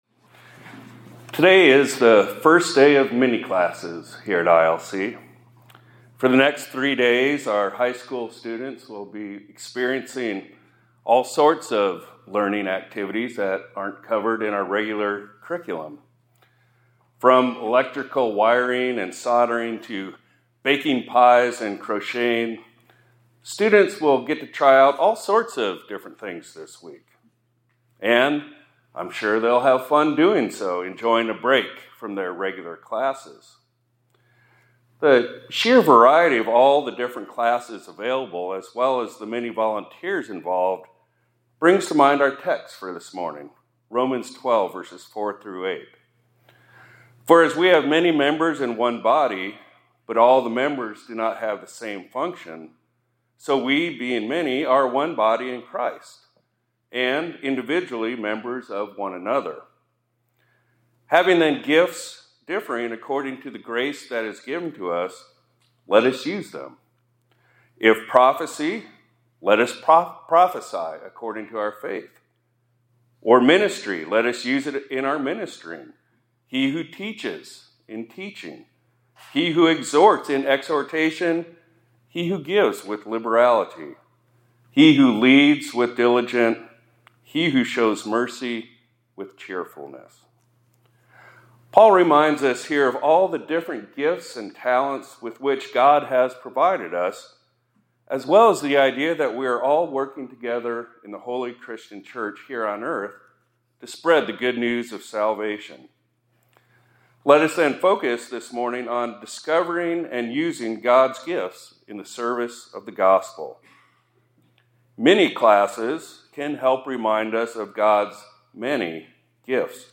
2025-02-19 ILC Chapel — Discovering and Using God’s Gifts in the Service of the Gospel